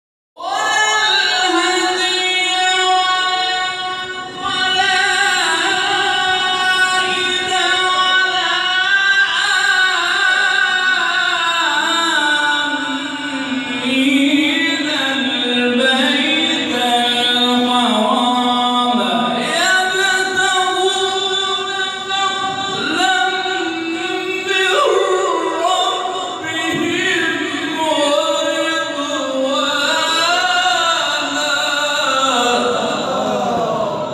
شبکه اجتماعی: نغمات صوتی از تلاوت قاریان برجسته و ممتاز کشور که به‌تازگی در شبکه‌های اجتماعی منتشر شده است، می‌شنوید.